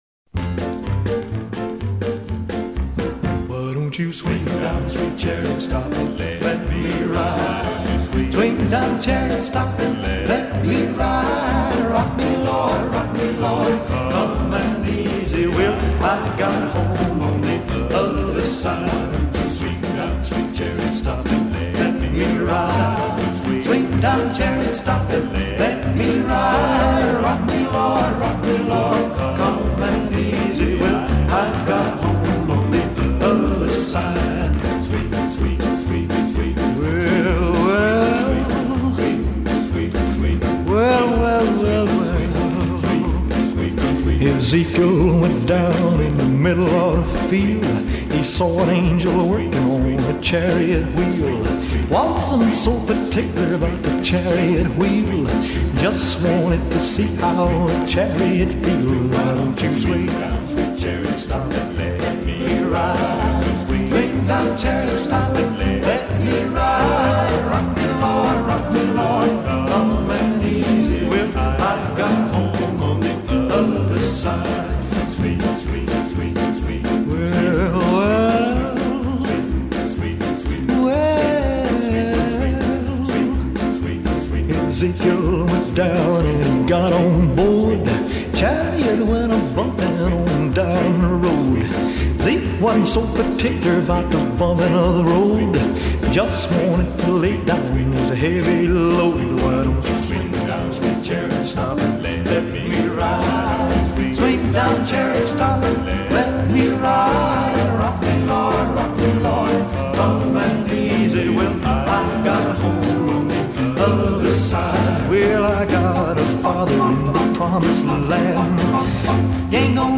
音乐类型：西洋音乐